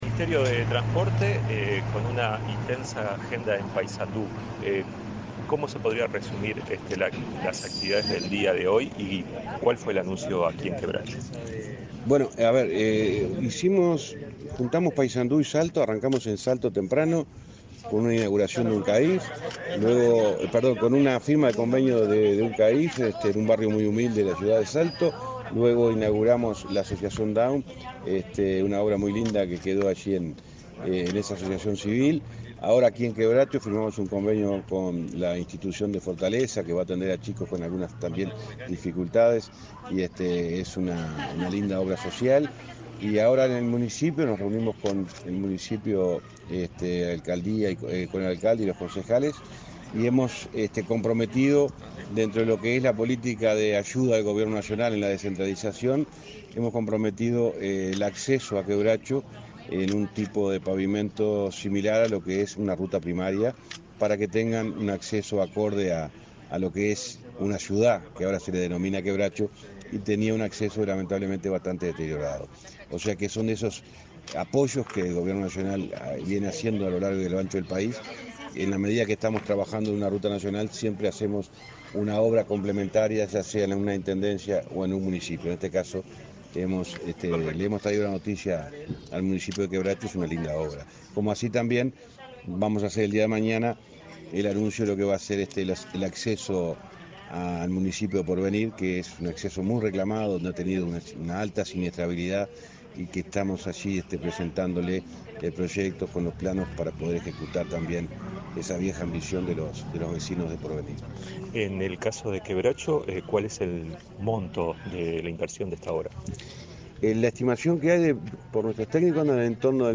Entrevista al ministro de Transporte y Obras Públicas, José Luis Falero
En el marco de una visita a Paysandú y Salto, el ministro de Transporte y Obras Públicas, José Luis Falero, en diálogo con Comunicación Presidencial,